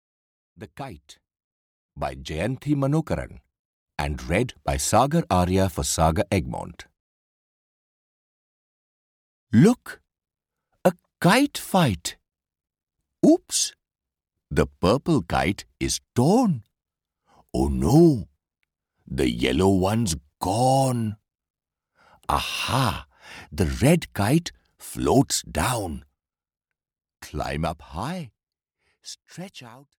The Kite (EN) audiokniha
Ukázka z knihy